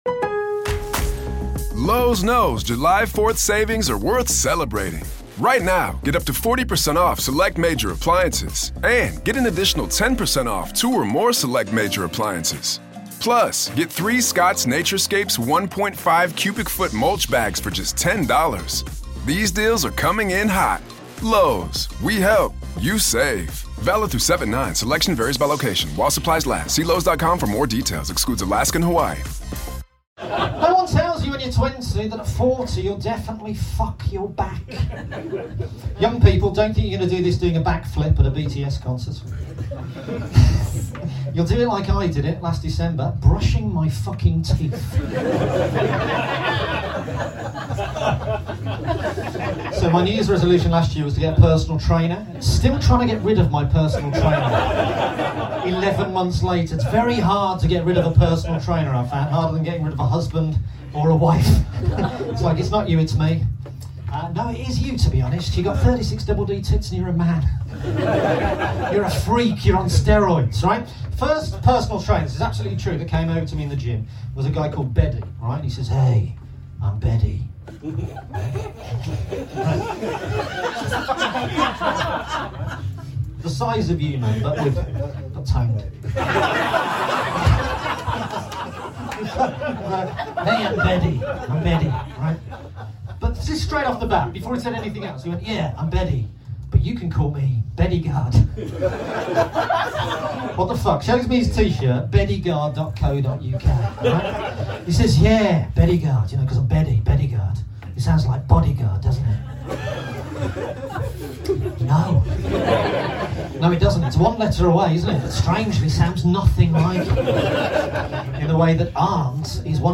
Recorded Live at Camden Comedy, London, Nov 2023.